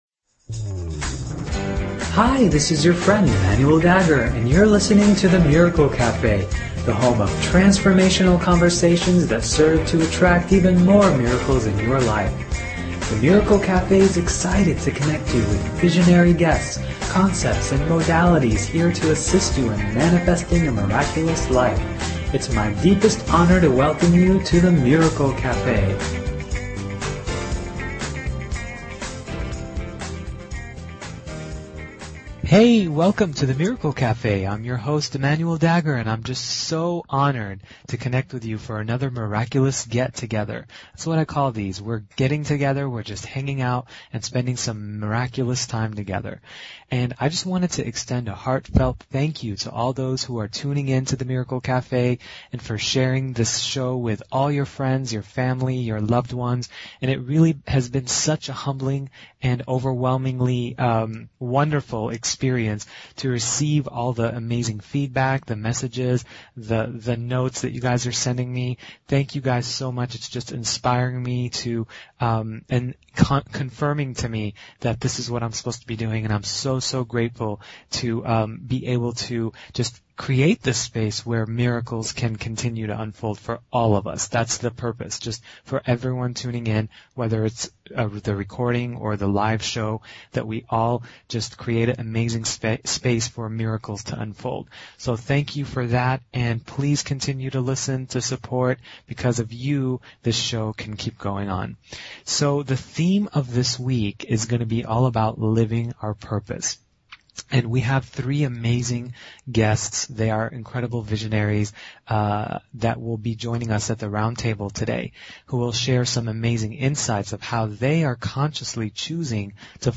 Talk Show Episode, Audio Podcast, The_Miracle_Cafe and Courtesy of BBS Radio on , show guests , about , categorized as
The Miracle Café is a radio show that is here to provide each listener with life-changing insights, processes, and conversations that serve to attract even more miracles and positive transformation in their life!